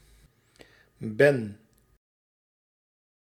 Ääntäminen
Synonyymit mand korf slof Ääntäminen Tuntematon aksentti: IPA: /bɛn/ Haettu sana löytyi näillä lähdekielillä: hollanti Kieli Käännökset ranska corbeille , panier suomi kori Ben on sanan zijn taipunut muoto.